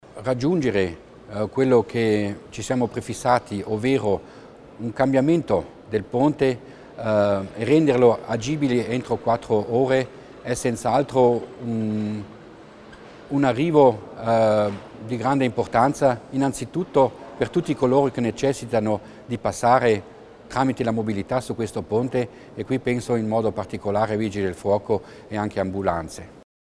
L'Assessore Mussner spiega l'utilità degli interventi sui ponti